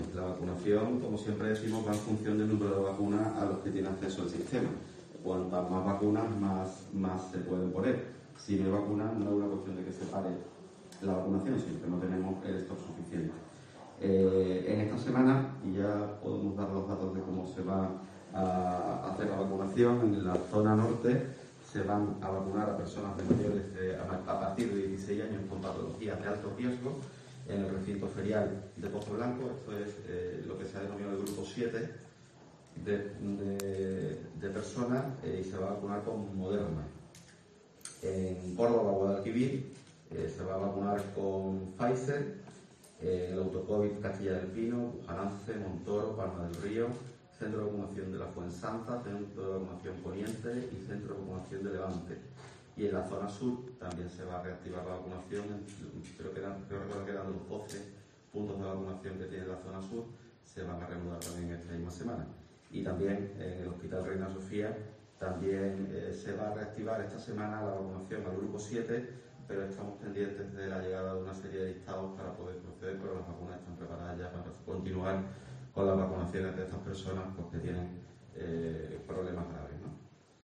En este sentido y en rueda de prensa, Repullo ha explicado que "la vacunación va en función del número de vacunas a las que tiene acceso el sistema", de modo que, "cuantas más vacunas" haya disponibles, "más se pueden poner", pero "si no hay vacunas, no es una cuestión de que se pare la vacunación, sino que no tenemos 'stock' suficiente".